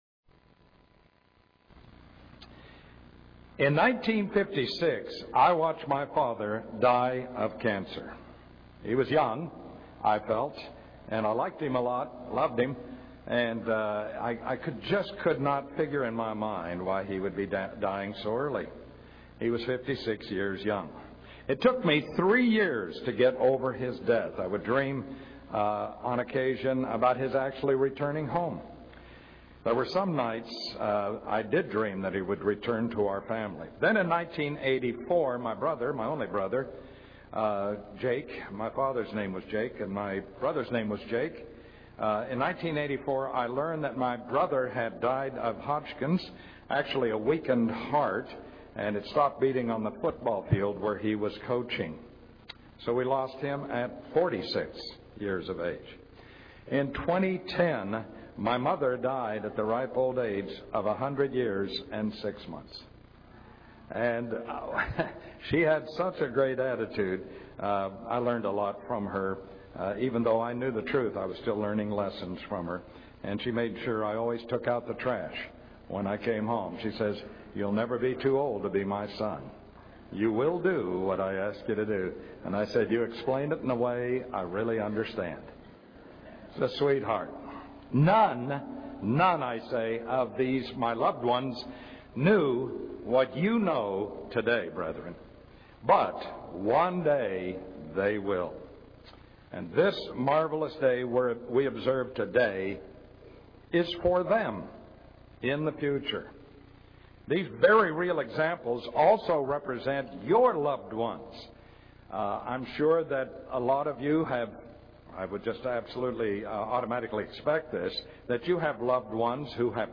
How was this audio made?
This sermon was given at the Panama City Beach, Florida 2012 Feast site.